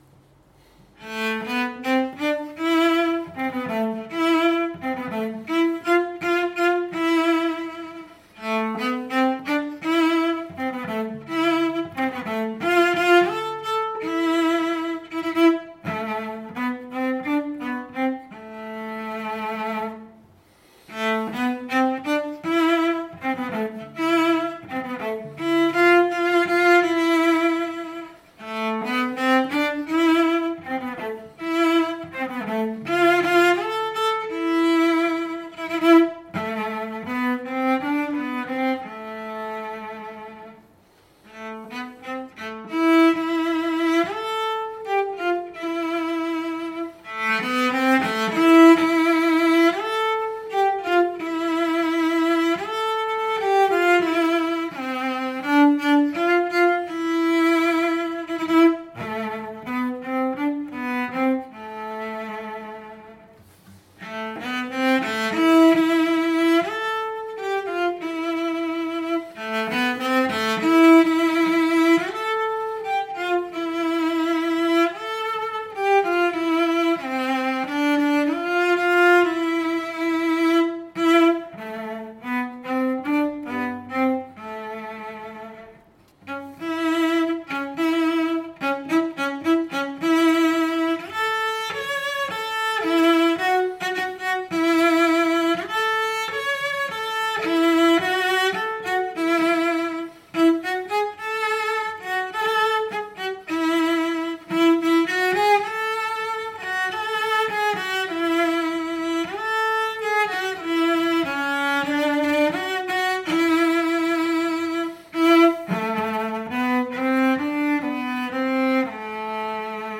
בביצוע צ’לו